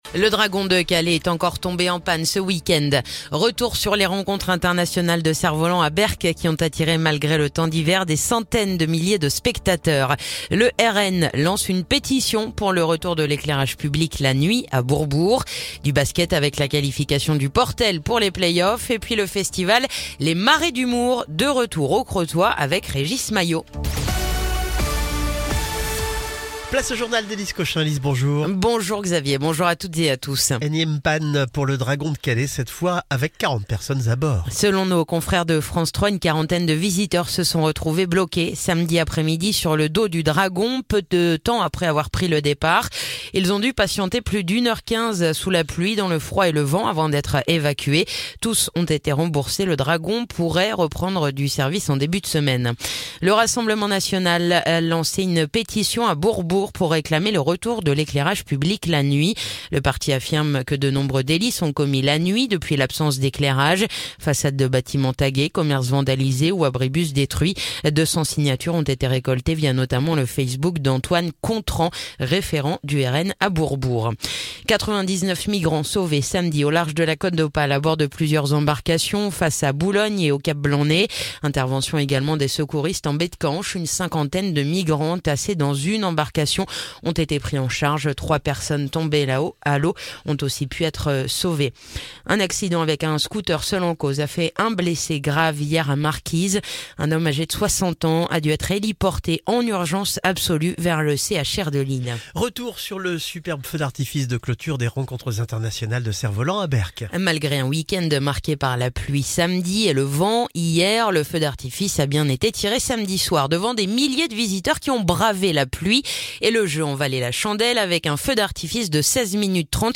Le journal du lundi 29 avril